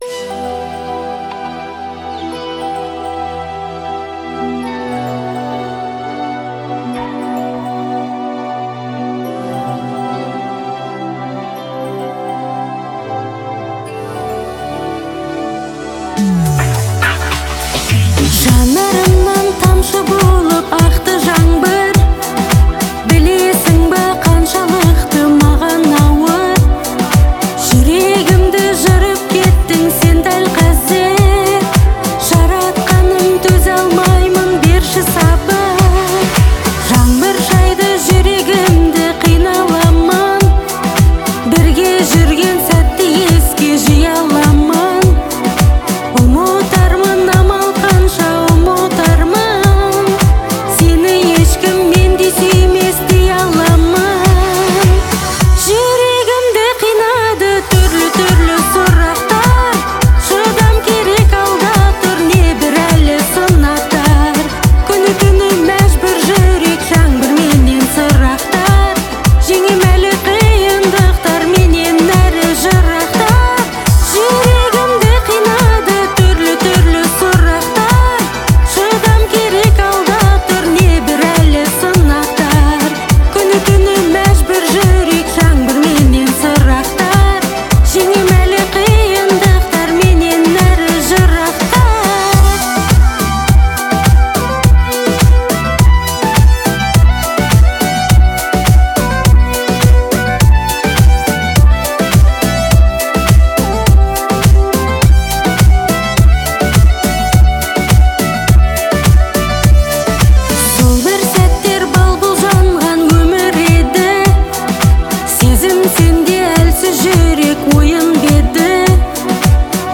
Музыка сочетает в себе мелодичность и душевность